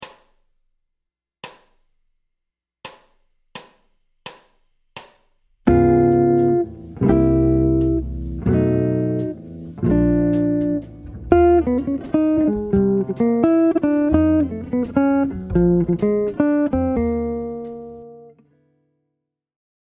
Blue Moon : #13 voicing et solo